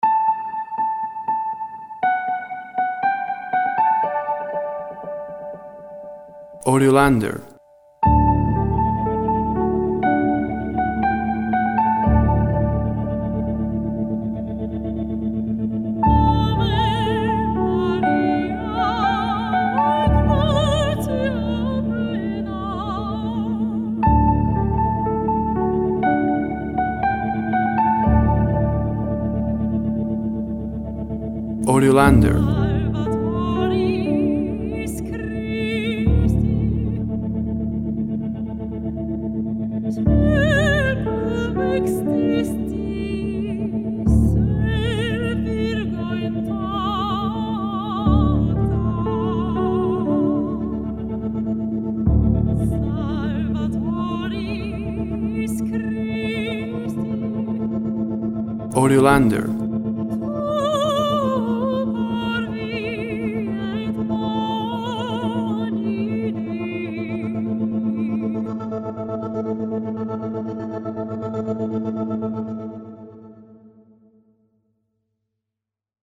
Ethereal atmospheres with female voice singing in Latin.